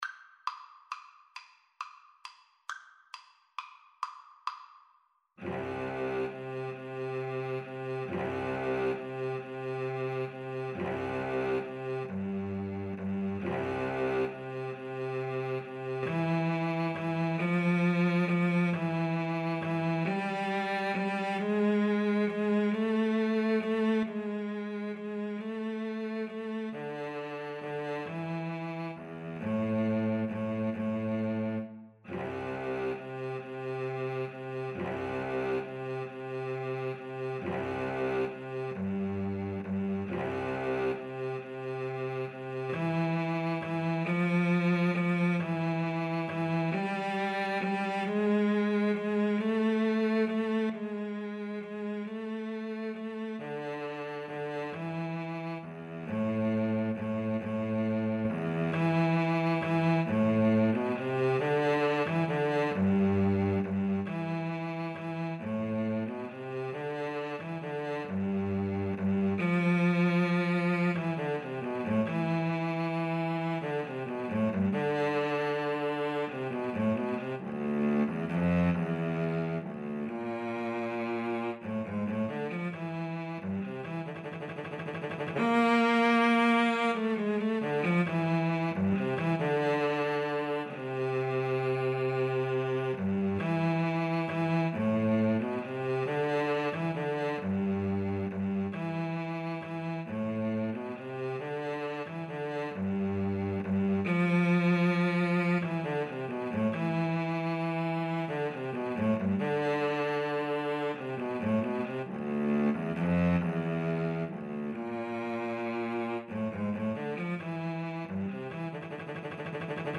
Andantino .=c.45 (View more music marked Andantino)
6/8 (View more 6/8 Music)
Classical (View more Classical Violin-Cello Duet Music)